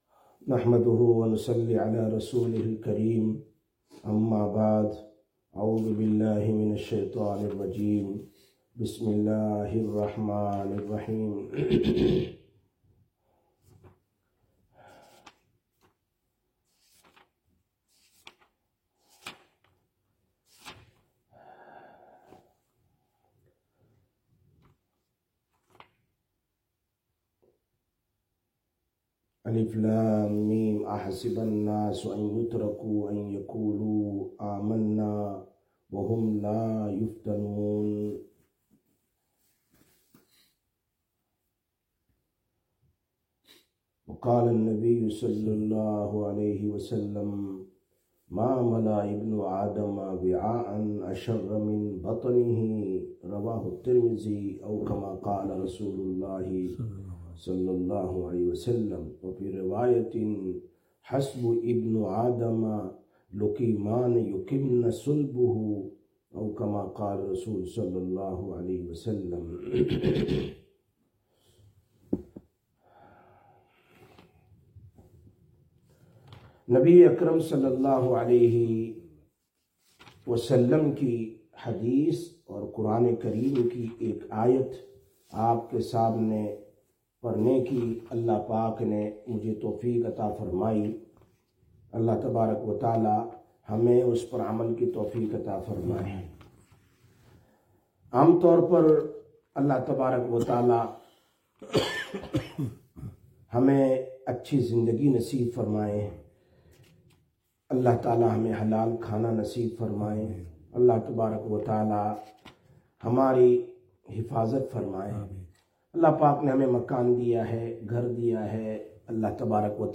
06/11/2024 Sisters Bayan, Masjid Quba